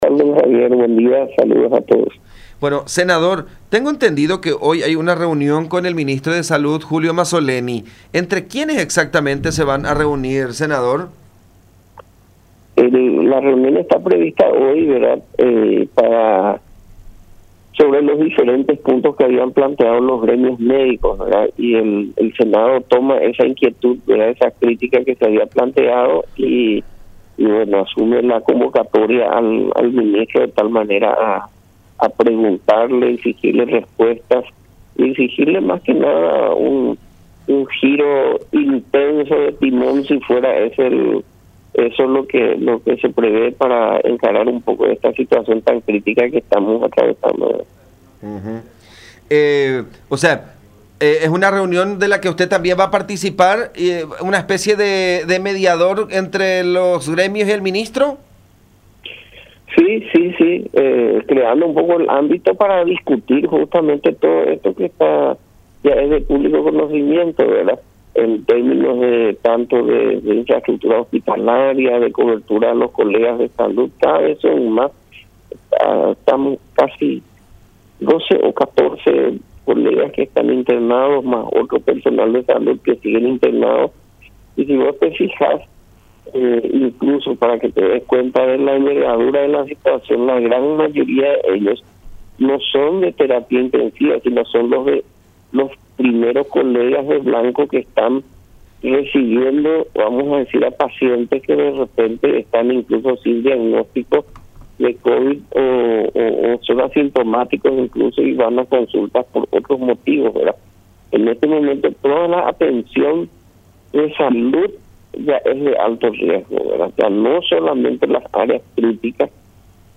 “Vamos a pedirle respuestas a Mazzoleni, vamos a exigirle un giro intenso de timón, si ese fuera eso lo que se prevé, para encarar esta situación tan crítica que estamos atravesando”, expresó Jorge Querey, senador del Frente Guasu, en contacto con La Unión, quien participará en la reunión como un “mediador” entre el gremio de los médicos y el titular de la cartera sanitaria.